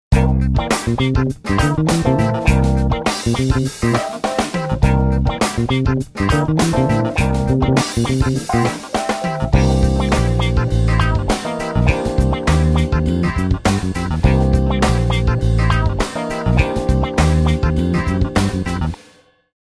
ベースちょーかっこいい曲のことだよ～。
ふぉおおおお、ファンクきたーーーっ！